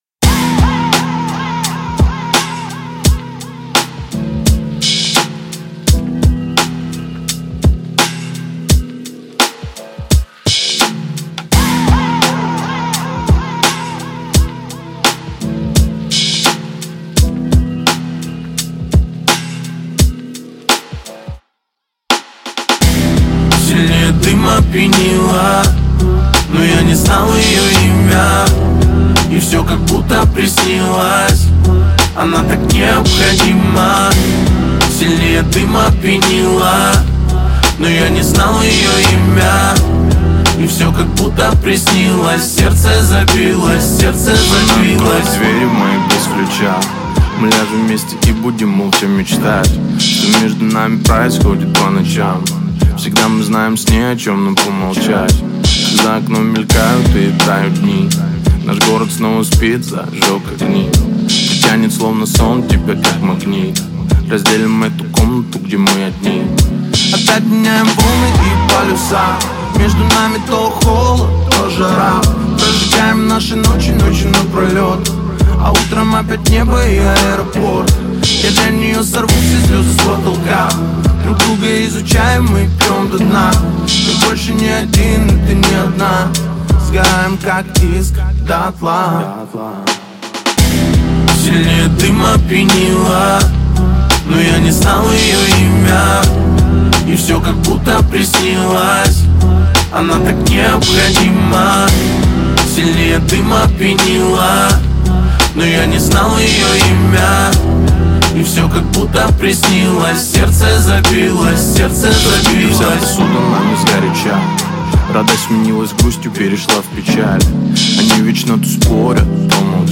Русский рэп
Жанр: Русский рэп / Хип-хоп / В машину